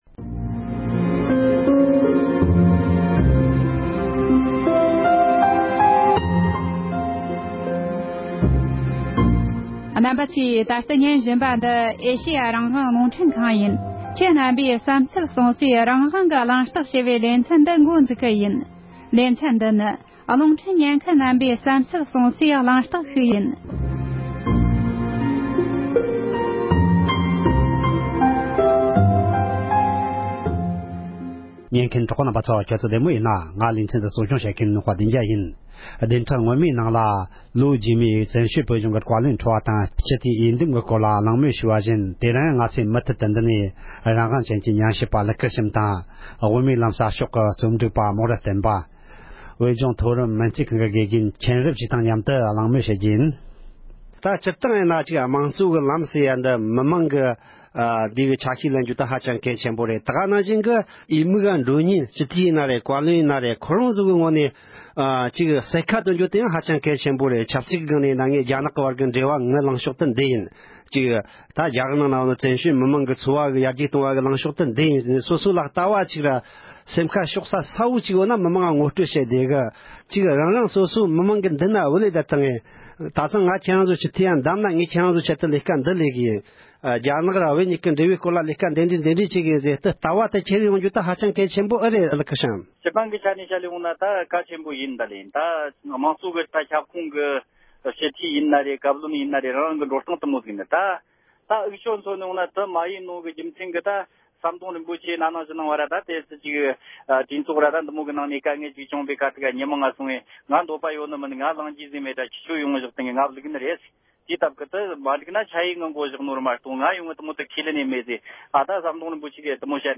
འབྱུང་འགྱུར་བཀའ་བློན་ཁྲི་པ་དང་བོད་མི་མང་སྤྱི་འཐུས་འོས་འདེམས་ཀྱི་གལ་ཆེན་རང་བཞིན་ཐད་མི་སྣ་ཁག་དང་གླེང་མོལ་ཞུས་པའི་དུམ་བུ་གཉིས་པ།